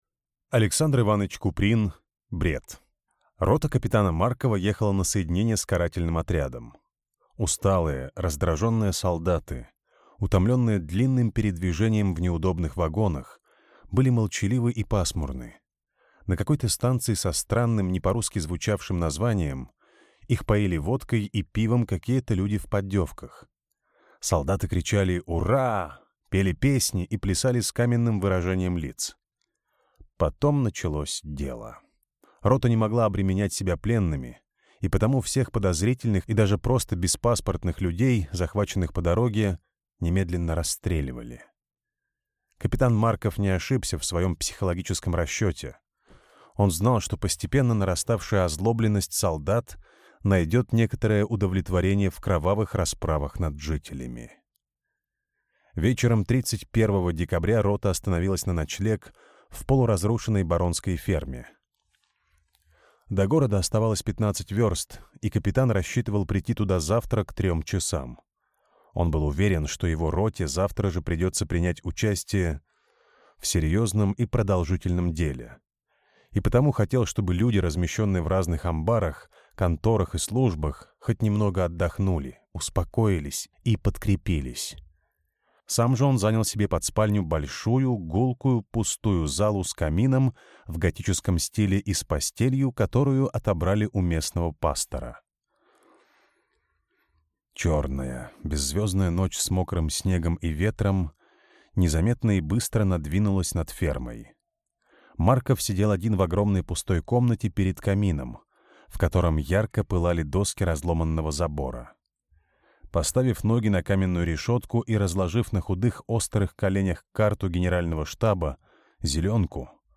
Аудиокнига Бред | Библиотека аудиокниг